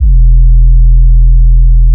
808_BigRoomEDM.wav